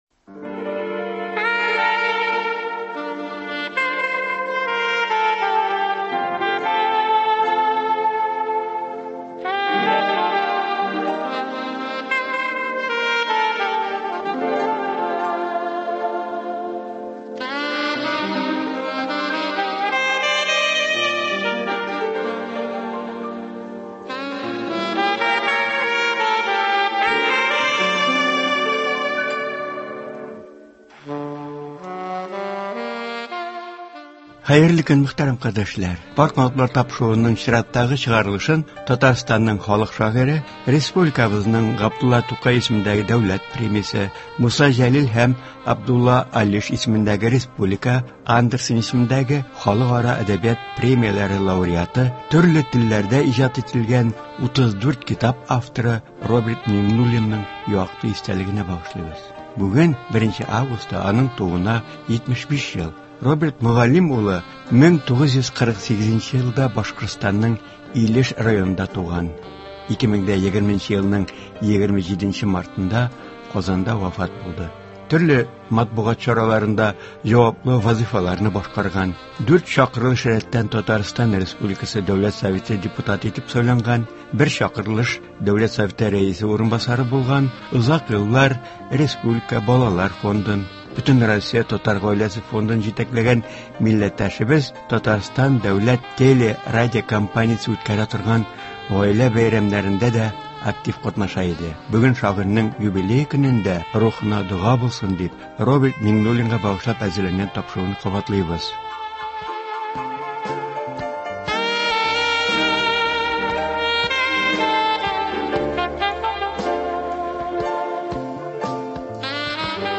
Тапшыруда Роберт Мөгаллим улы Татарстан республикасы Дәүләт Советы депутаты булган чакта язып алынган әңгәмә файдаланыла.